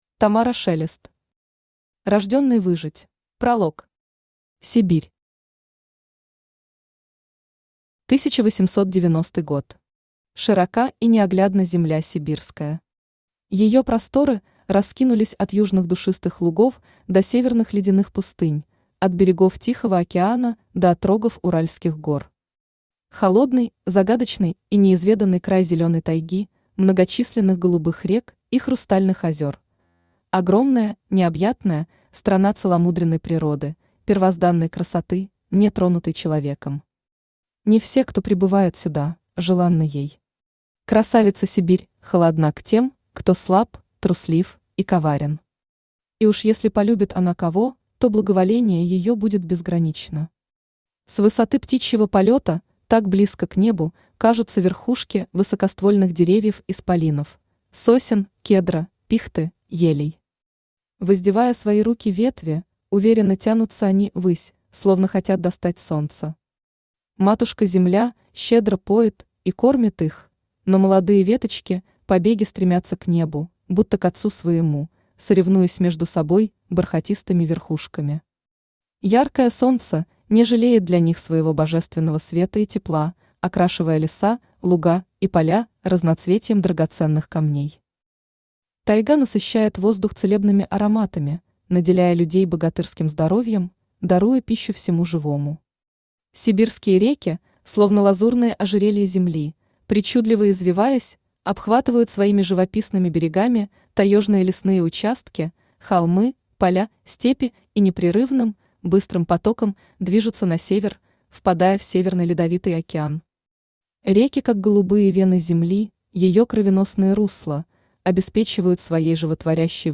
Аудиокнига Рождённый выжить | Библиотека аудиокниг
Aудиокнига Рождённый выжить Автор Тамара Шелест Читает аудиокнигу Авточтец ЛитРес.